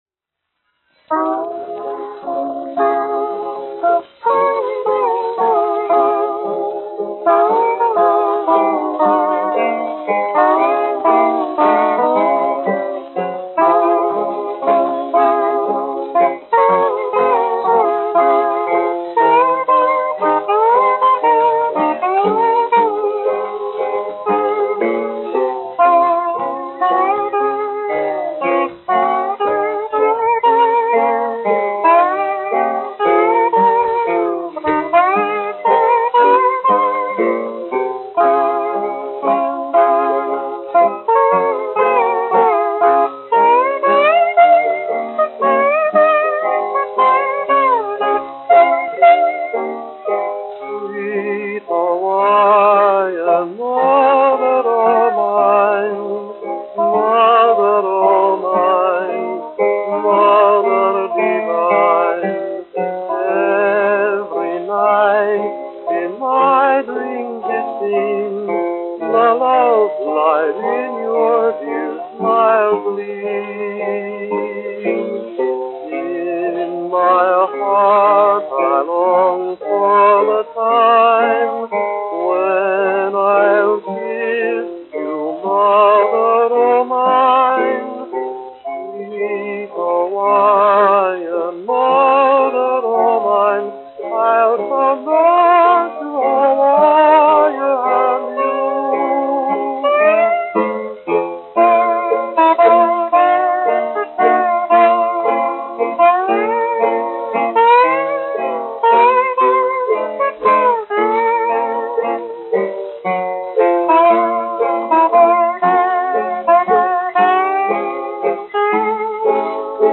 1 skpl. : analogs, 78 apgr/min, mono ; 25 cm
Populārā mūzika
Valši
Skaņuplate
Latvijas vēsturiskie šellaka skaņuplašu ieraksti (Kolekcija)